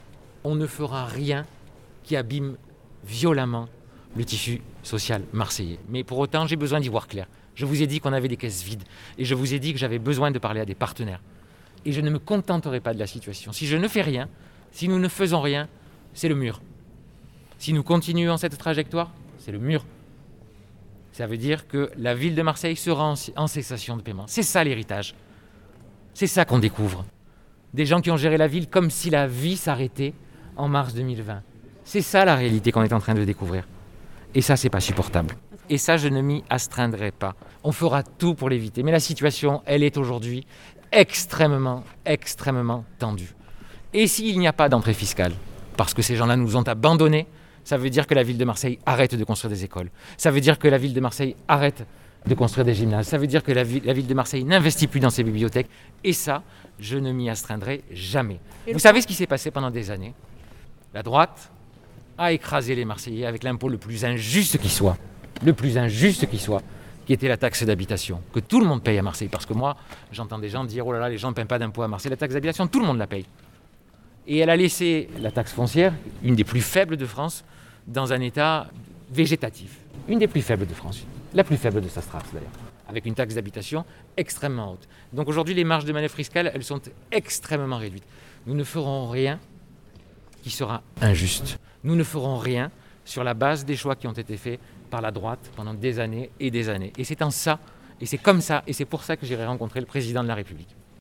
Entretien avec Benoît Payan